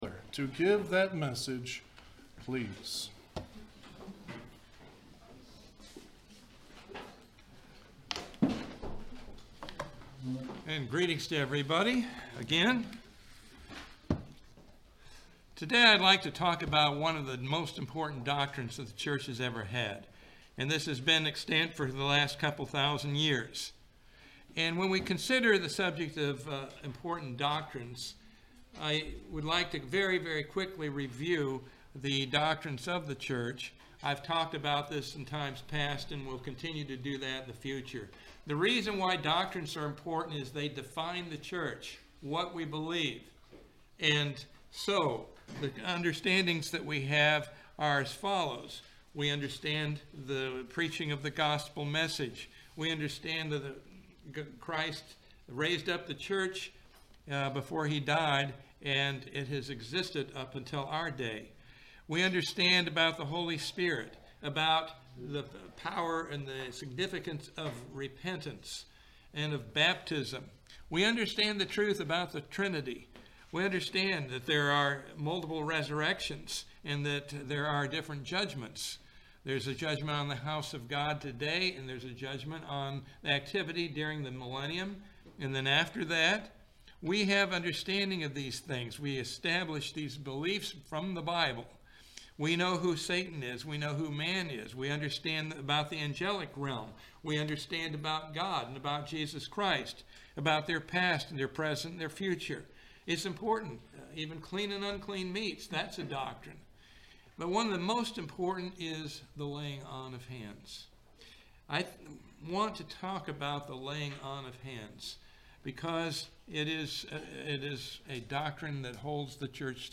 In this sermon, the importance of receiving God's Holy Spirit through the laying on of hands and also receiving His anointing is highlighted.
Given in Ocala, FL